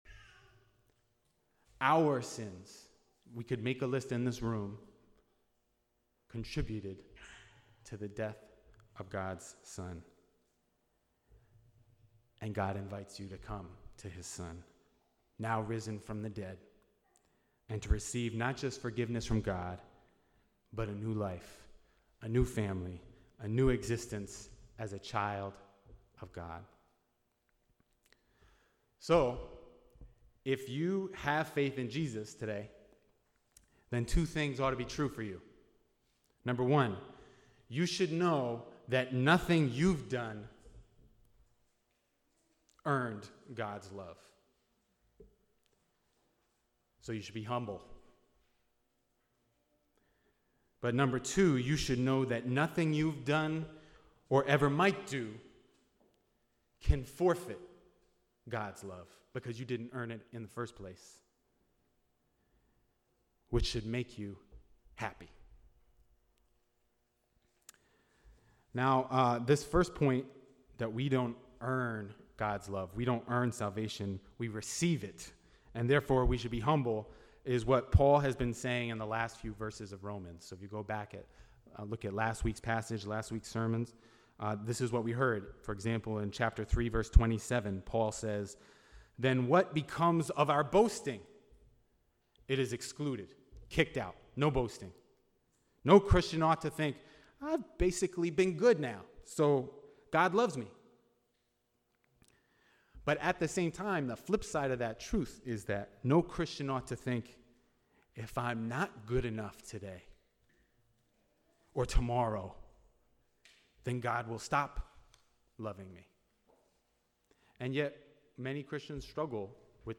NOTE: The audio of this sermon does not include the introductory illustration below.